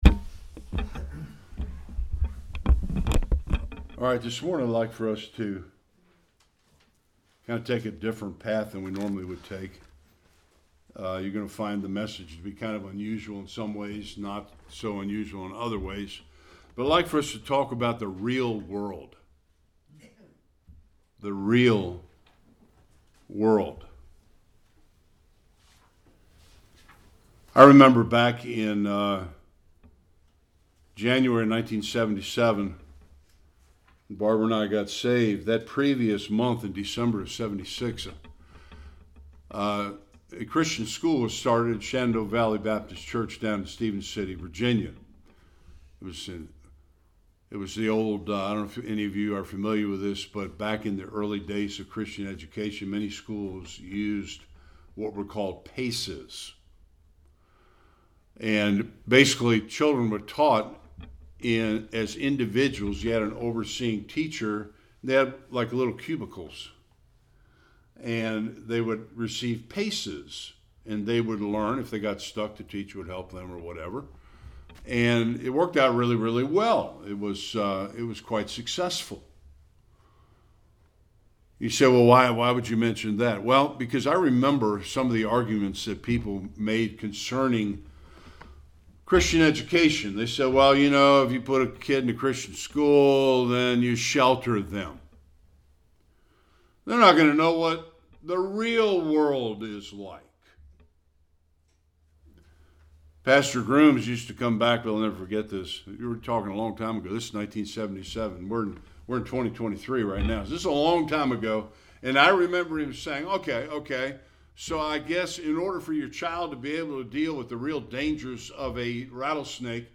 Various Passages Service Type: Sunday Worship Can the lost really understand reality?